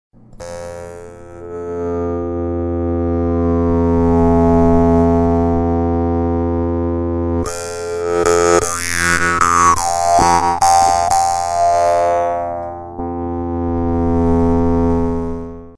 Die vietnamesischen Maultrommeln zeichnen sich durch ihre einfache Spielbarkeit, ihren schönen, obertonreichen Klang und den günstigen Preis aus.
Dabei erinnert ihr Sound an elektronische Klänge analoger Synthesizer – ganz ohne Technik.
Hörprobe Dan Moi Standard 5: